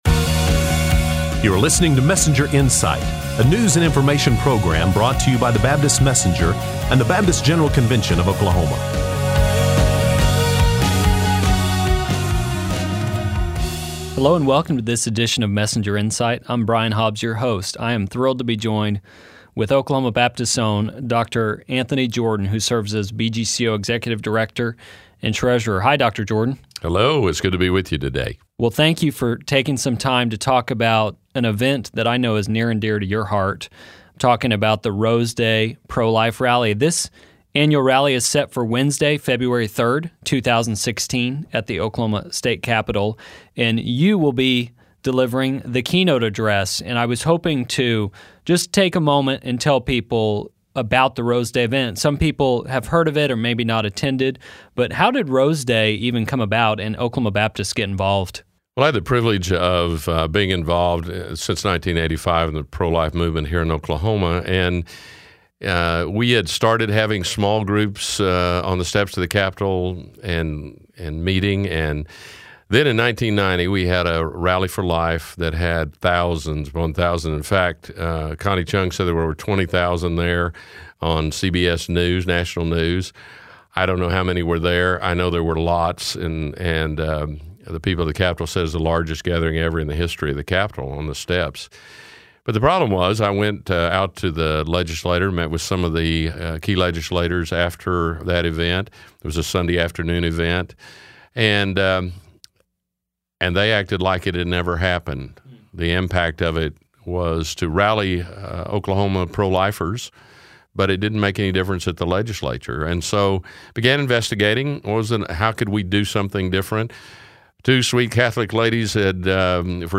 Interview about the upcoming Rose Day